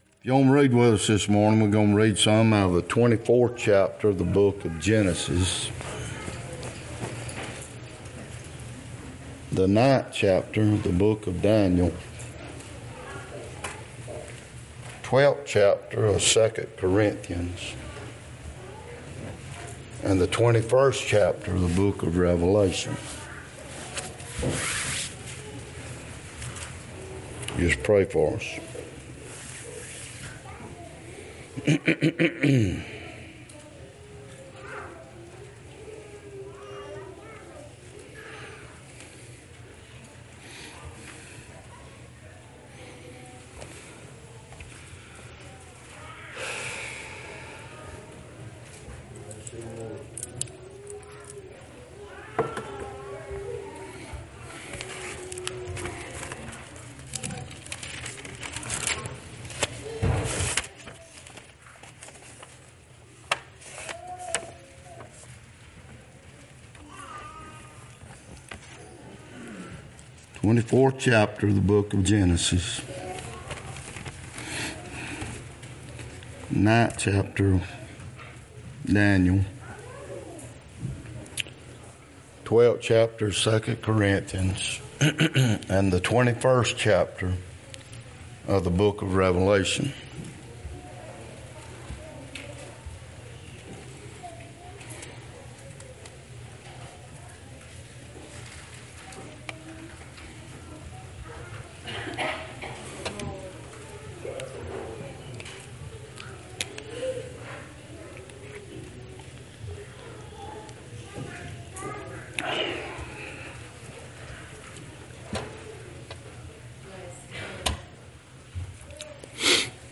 Genesis 24:1-26 Daniel 9:1-2 2 Corinthians 12:1-4 Revelation 21:9-13 Service Type: Sunday Topics